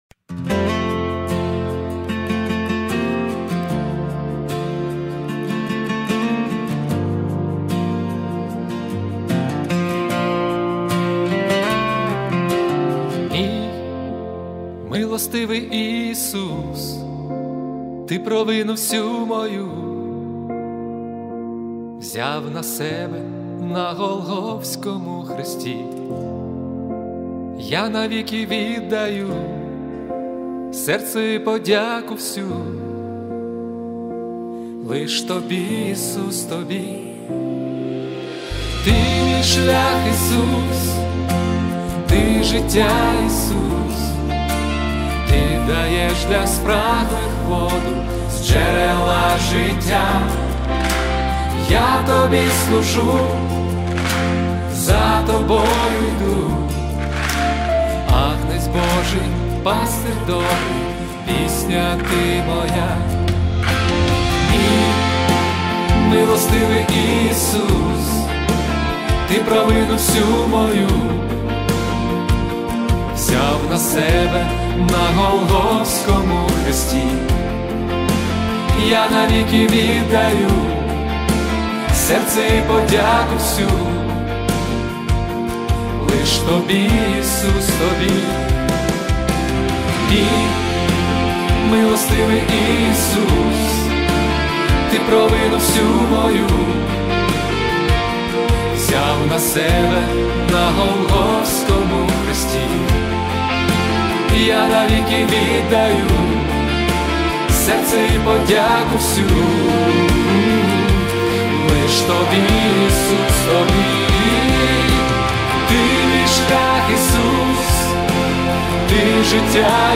40 просмотров 104 прослушивания 3 скачивания BPM: 75